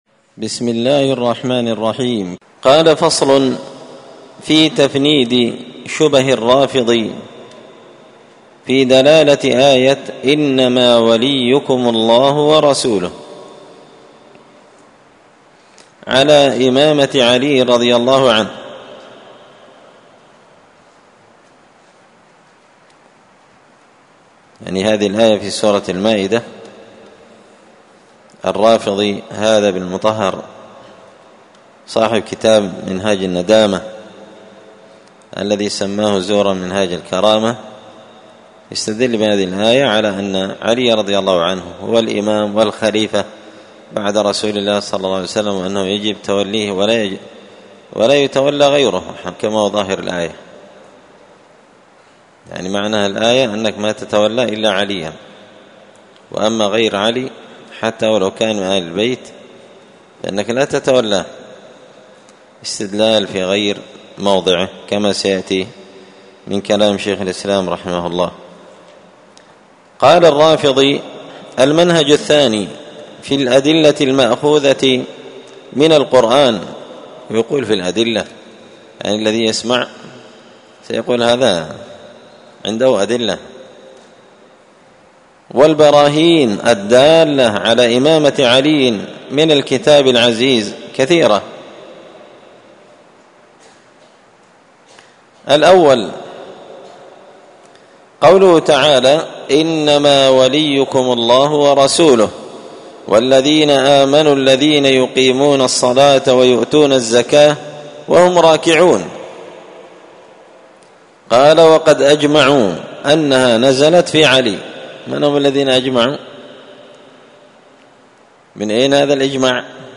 الأربعاء 22 محرم 1445 هــــ | الدروس، دروس الردود، مختصر منهاج السنة النبوية لشيخ الإسلام ابن تيمية | شارك بتعليقك | 70 المشاهدات